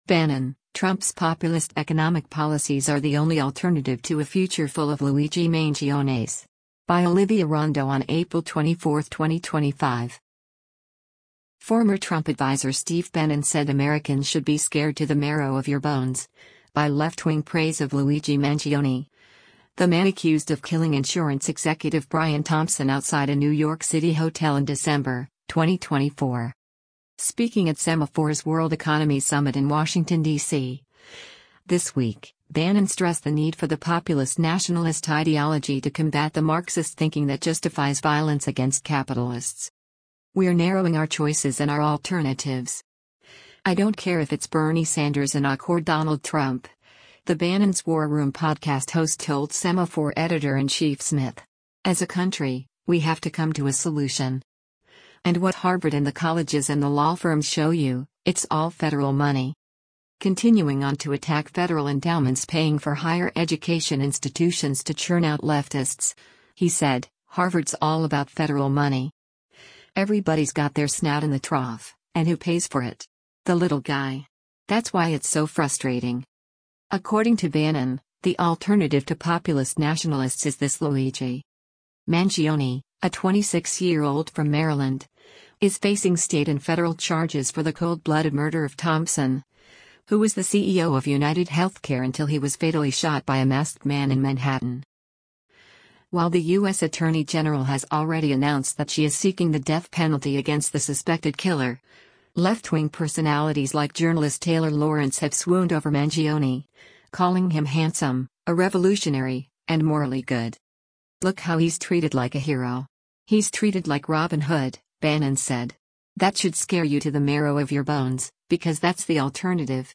Chief Strategist to the President Steve Bannon speaks during the Semafor World Economy Sum
Speaking at Semafor’s World Economy Summit in Washington, DC, this week, Bannon stressed the need for the populist nationalist ideology to combat the Marxist thinking that justifies violence against capitalists: